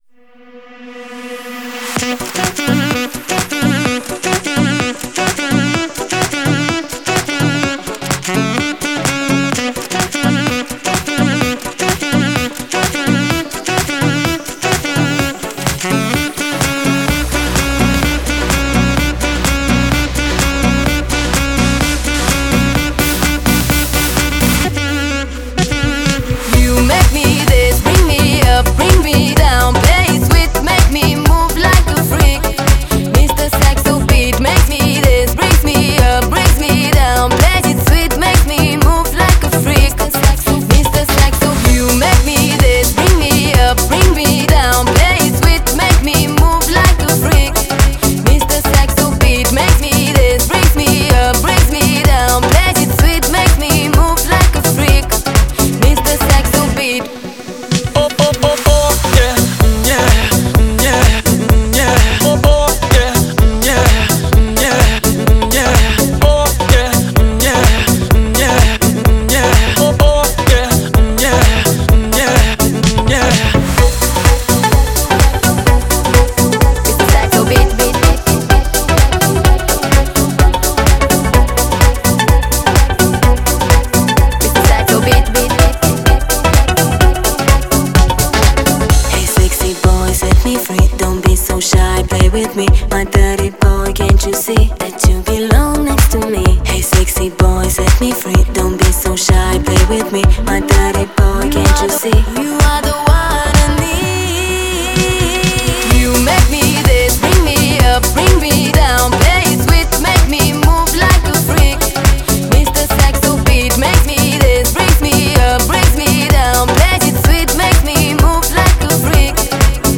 Style: Pop